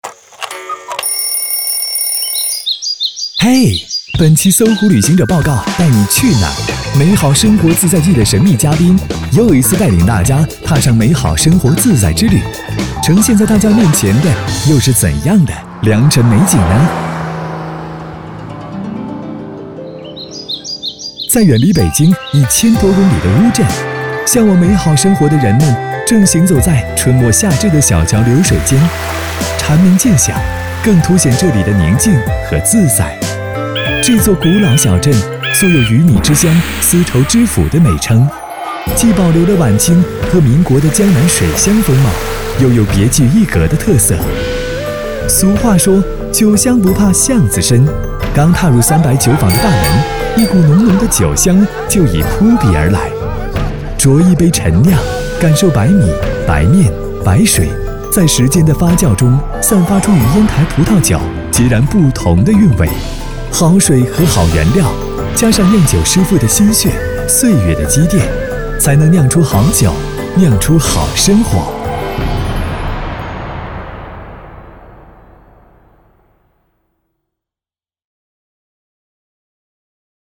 24 男国198_宣传片_旅游_旅游节目配音搜狐_时尚 男国198
男国198_宣传片_旅游_旅游节目配音搜狐_时尚.mp3